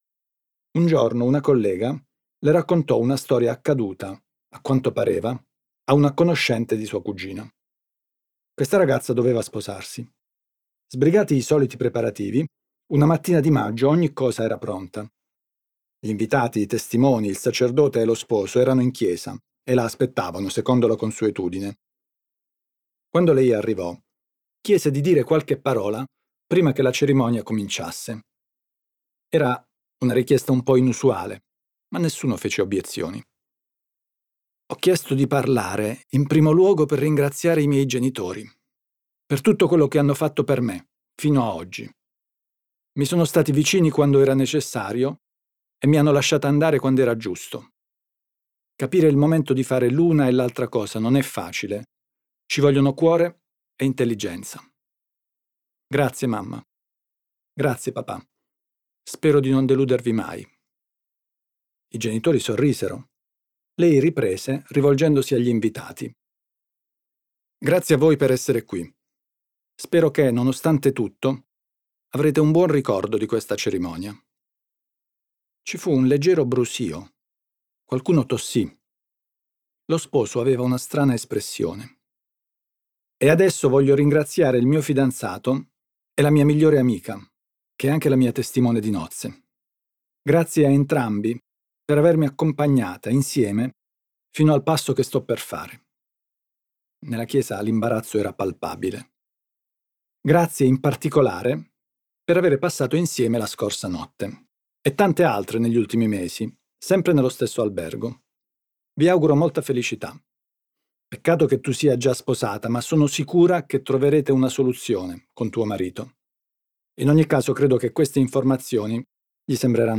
Audiolibro Emons audiolibri 2016